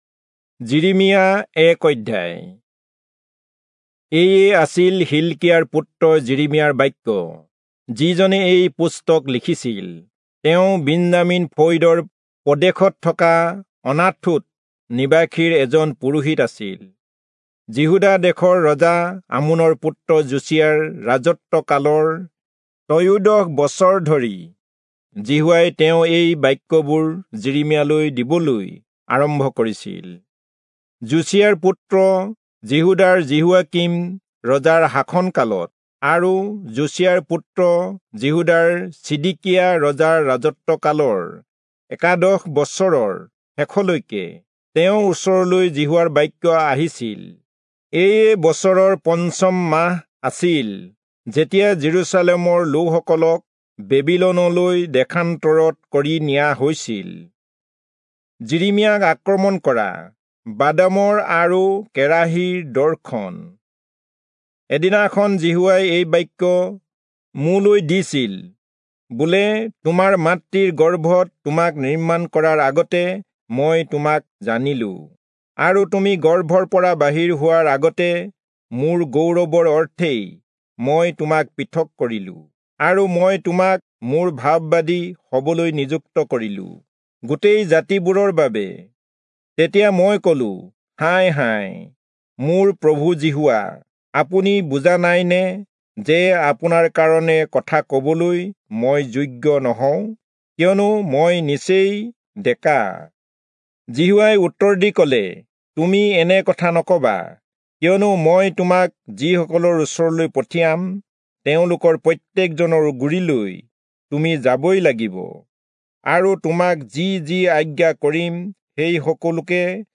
Assamese Audio Bible - Jeremiah 48 in Ocvbn bible version